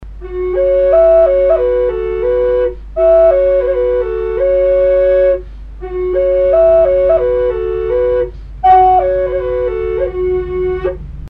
Пимак двойной F#
Пимак двойной F# Тональность: F#
Модификация пимака имеюшая два игровых канала, один из которых настроен в тонику. Играть можно как сразу в оба, так и в один, при этом используя как обычный пимак.
Пимак или "флейта любви" является национальным духовым инструментом североамериканских индейцев.